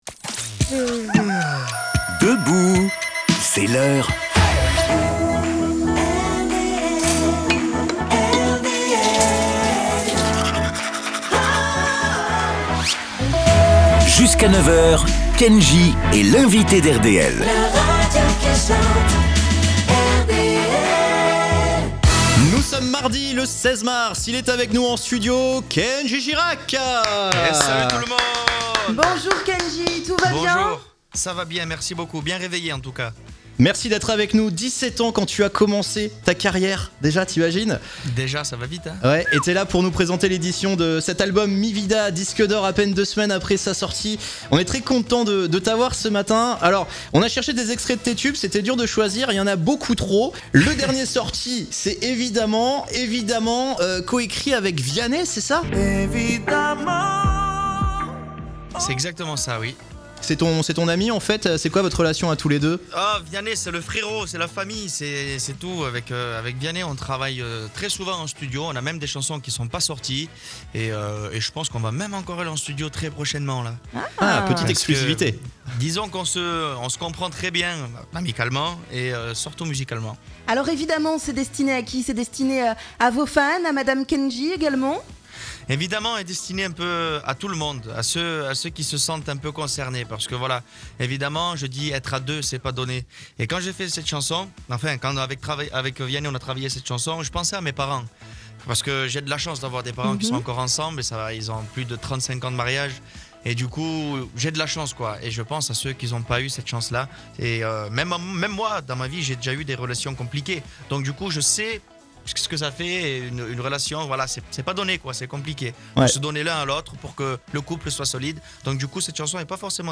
A l'occasion de l'édition de son album "Mi Vida", réécoutez l'interview de Kendji sur RDL !